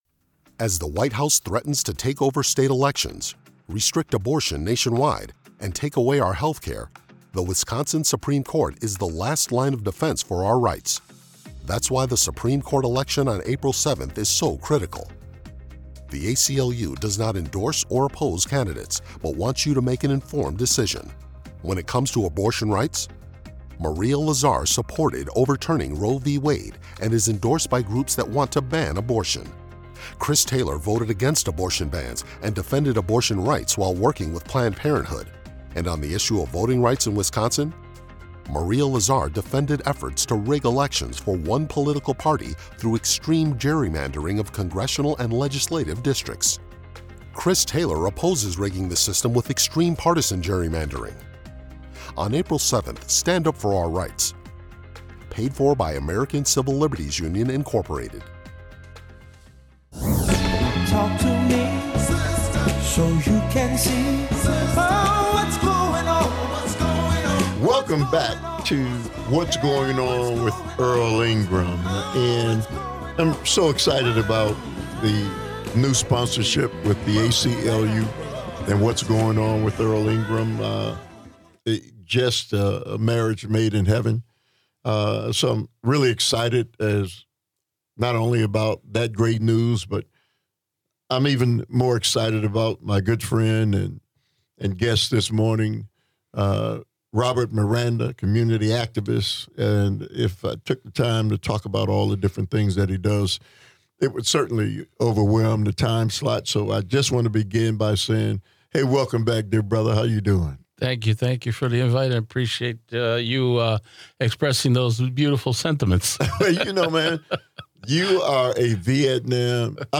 This is a thought-provoking conversation about war, diplomacy, sacrifice, and why we need to wake up and pay attention before the costs come home to our communities.